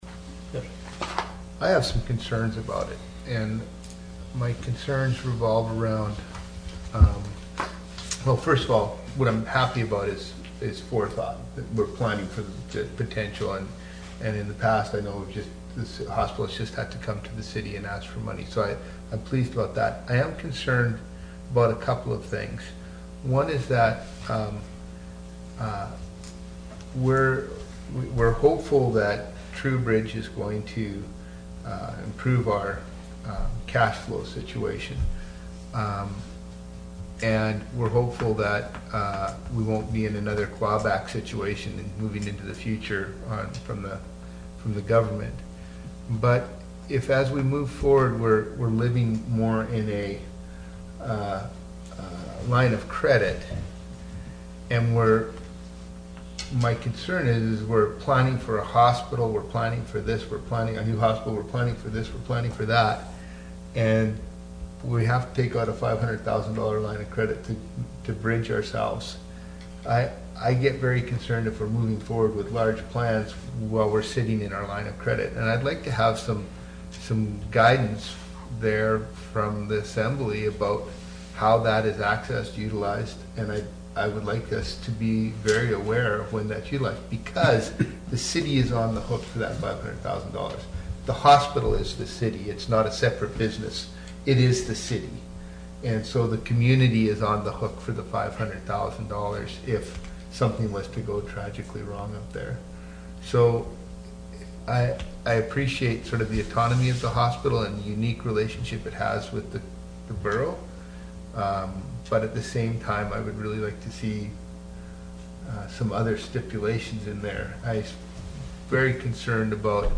Wrangell's Borough Assembly held its regular meeting Tuesday, July 28 in the Assembly Chambers.